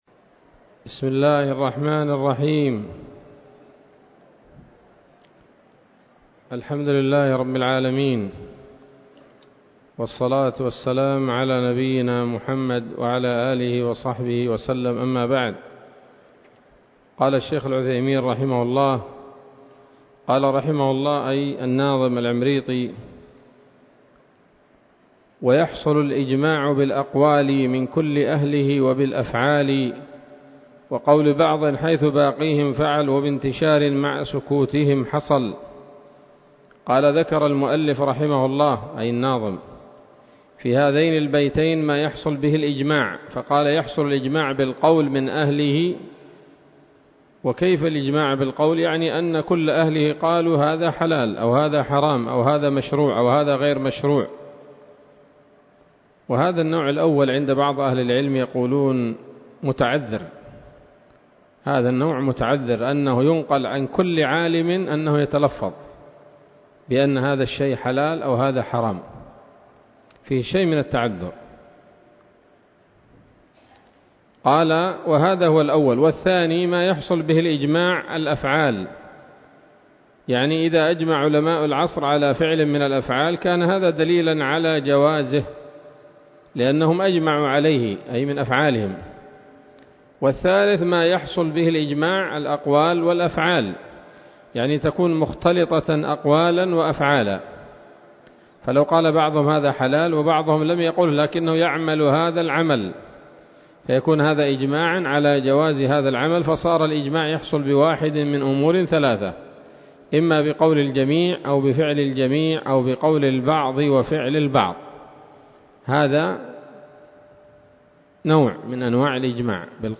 الدرس السادس والخمسون من شرح نظم الورقات للعلامة العثيمين رحمه الله تعالى